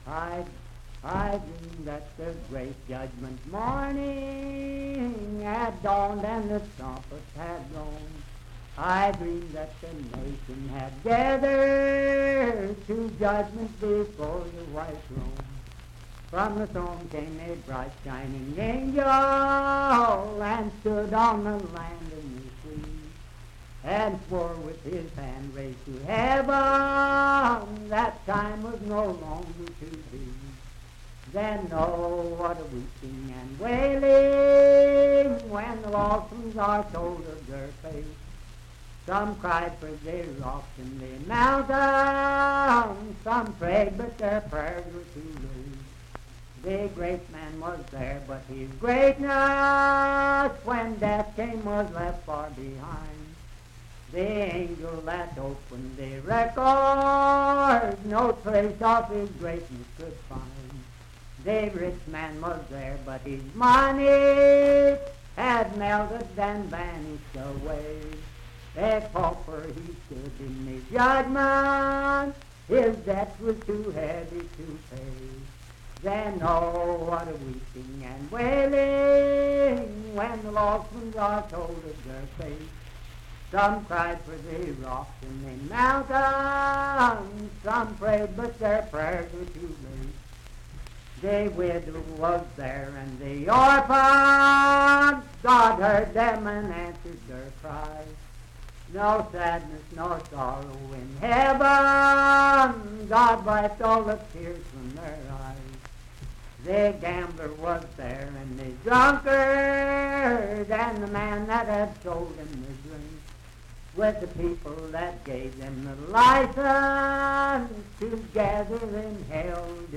Verse-refrain 3(8) & R(4).
Hymns and Spiritual Music
Voice (sung)
Parkersburg (W. Va.), Wood County (W. Va.)